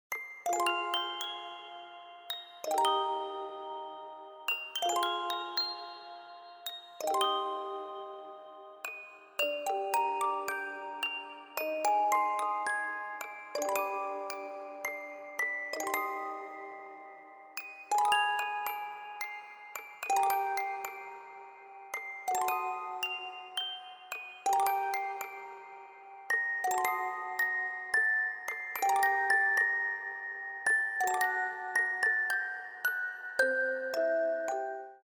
колыбельные , инструментальные , без слов